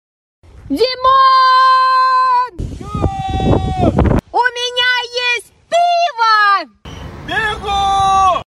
Звуки пива